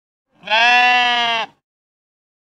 sheep-bleet.ogg.mp3